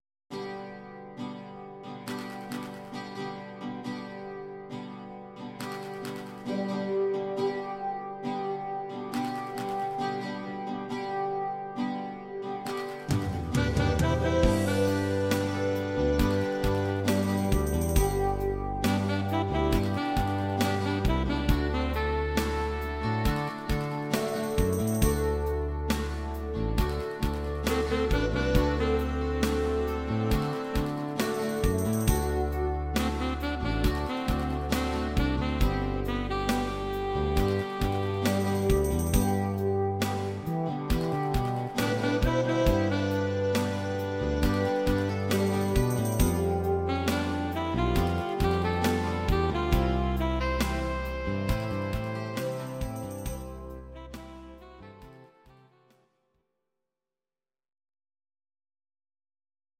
Audio Recordings based on Midi-files
Pop, German, 1970s